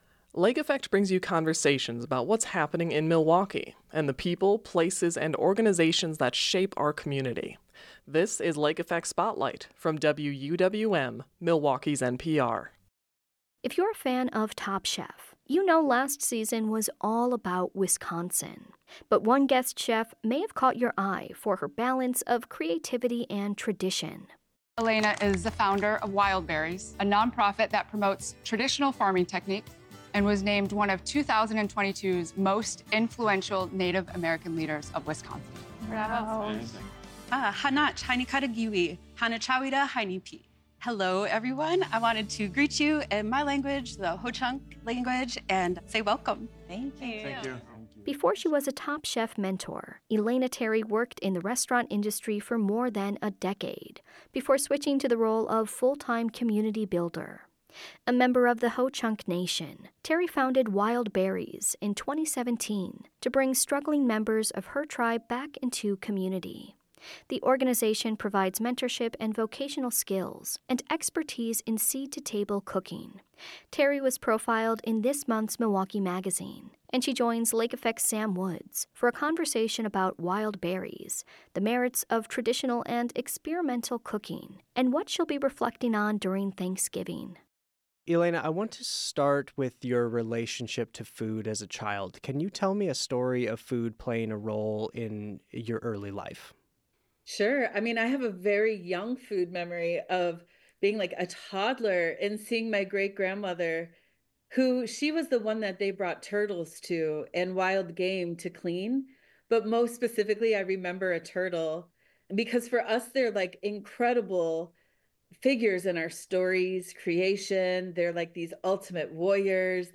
The Lake Effect Spotlight podcast features some of our favorite conversations about the people, places and organizations that shape Milwaukee.Learn more about Lake Effect here.